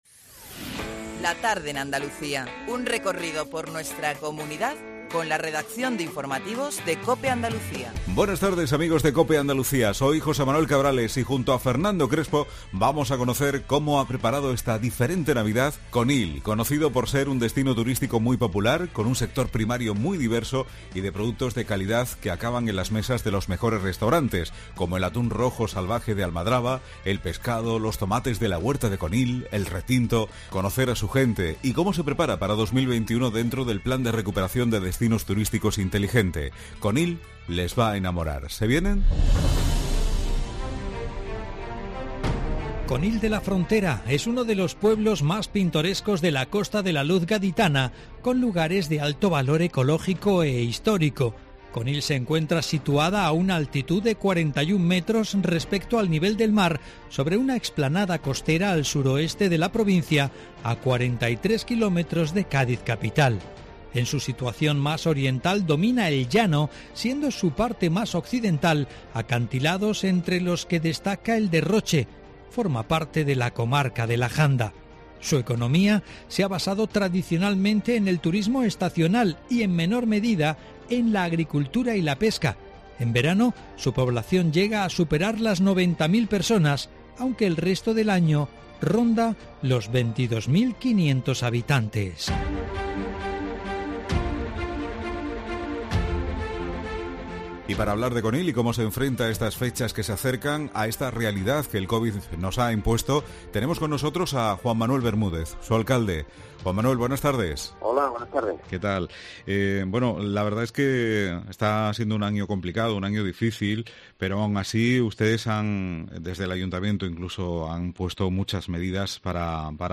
Hablamos de Conil y cómo se enfrenta en estas fechas que se acercan, a esta realidad que el covid nos ha impuesto, Juan Manuel Bermúdez, su alcalde nos cuenta detalles de estas fechas navideñas y cómo se va a hacer lo posible por conseguir que no se pierda la ilusión por los más pequeños, las actividades que el Ayuntamiento de Conil va a poner en marcha, orientadas siempre para ellos, la materia prima de calidad que aporta Conil a la gastronomía y cómo se prepara la recuperación para 2021.
Para incentivar esas compras locales que más que nunca se necesitan, tenemos a la Concejala de Desarrollo Económico, Mary Carmen García Caro.